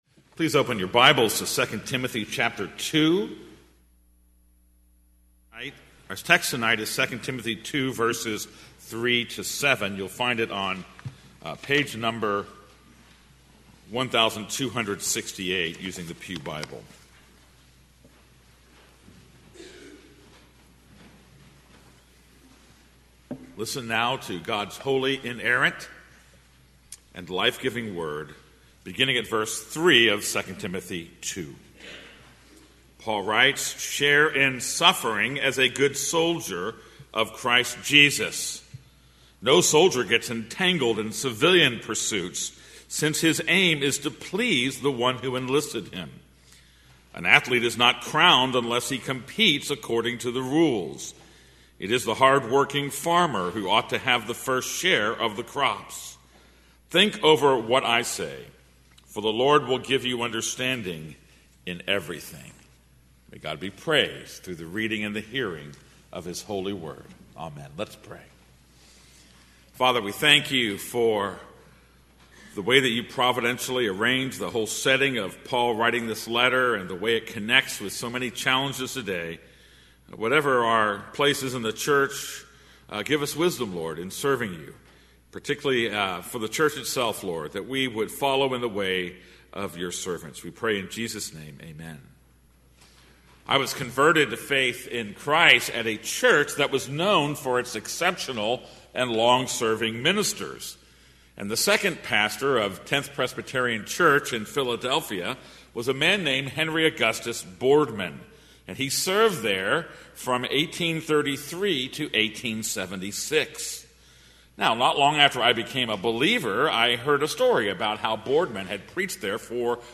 This is a sermon on 2 Timothy 2:3-7.